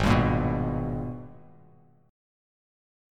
G#mM9 chord